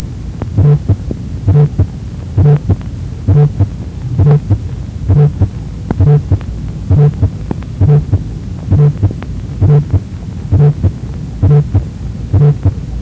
Early systolic murmur  พบในภาวะ small VSD, VSD with PH มีลักษณะเป็น cresendo
decresendo murmur